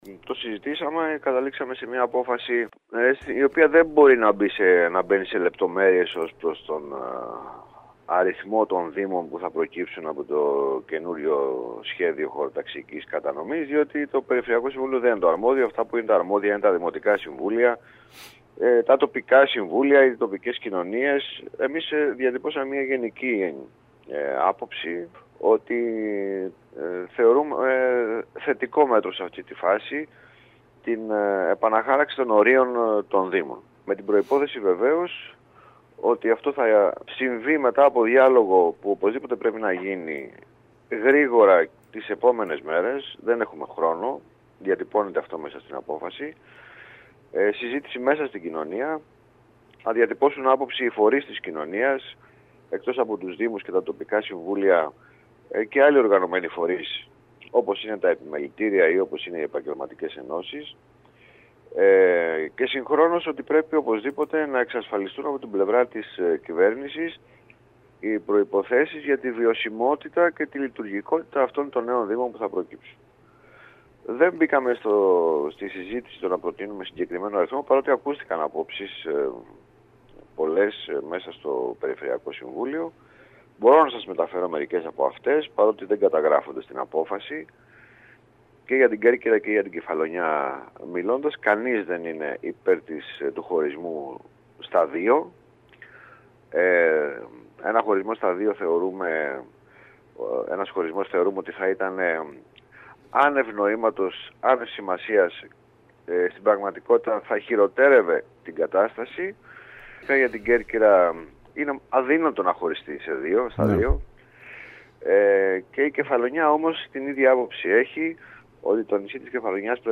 Υπέρ της διοικητικής διαίρεσης σε Κέρκυρα και Κεφαλονιά τάχθηκε το Περιφερειακό Συμβούλιο που συνεδρίασε το Σαββατοκύριακο στο Αργοστόλι. Μιλώντας στην ΕΡΤ Κέρκυρας, ο πρόεδρος του σώματος Χρήστος Μωραϊτης, τόνισε ότι το Περιφερειακό Συμβούλιο, δεν μπήκε σε λεπτομέρειες που αφορούν τον αριθμό των Δήμων αφού κάτι τέτοιο δεν αφορά τις αρμοδιότητές του.